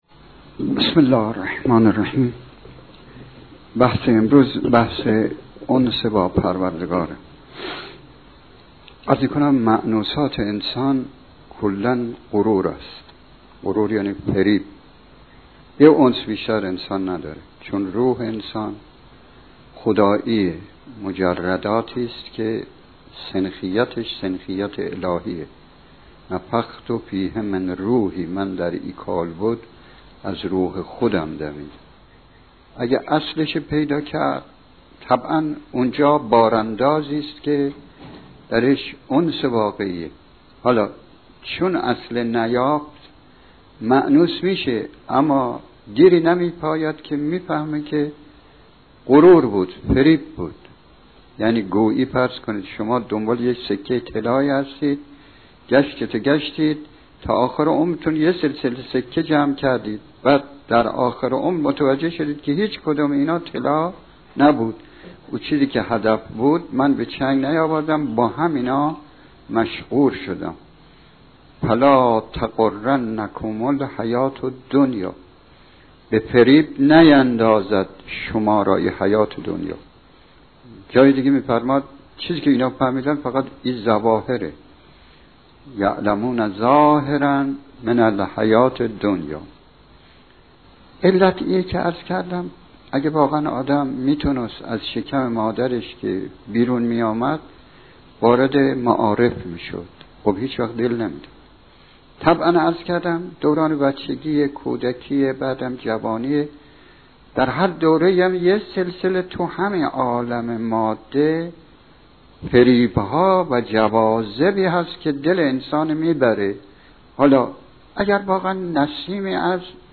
جلسات سخنرانی